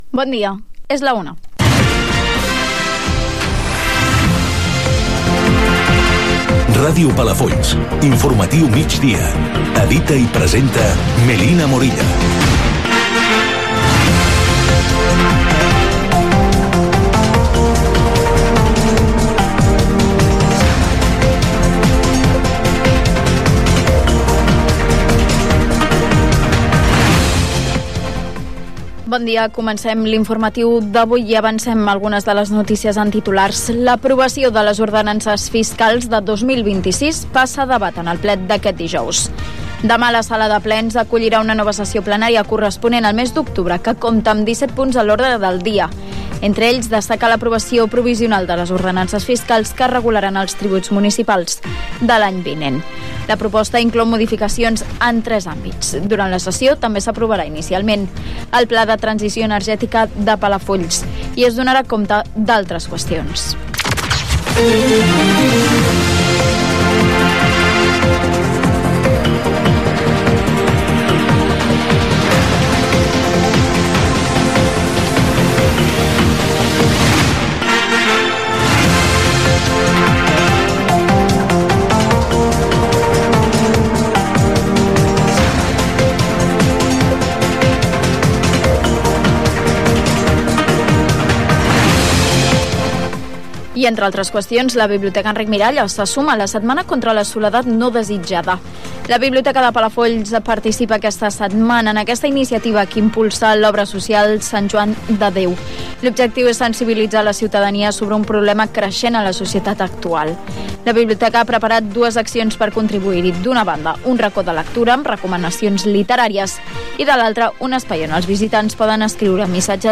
Hora, careta del programa, resum de titulars, el temps, el ple de l'Ajuntament de Palafolls del mes d'octubre
Informatiu